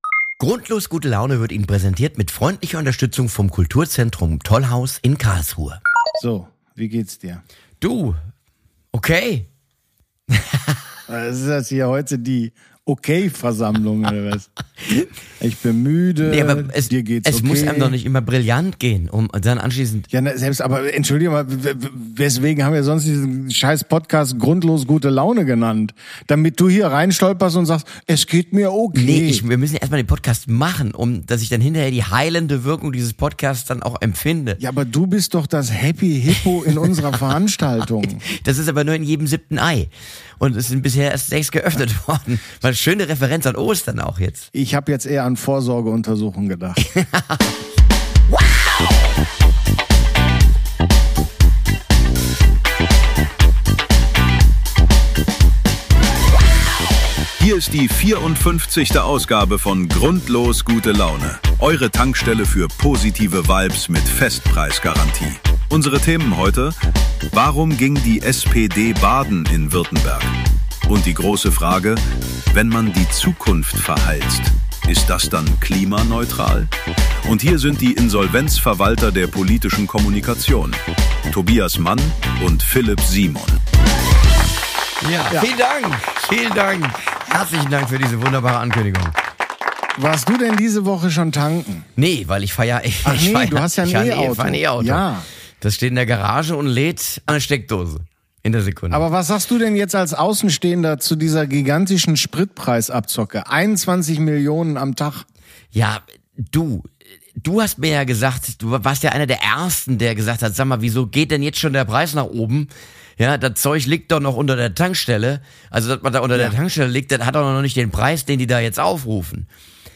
Zwei Podcaster kurz vorm Mikropfeifen.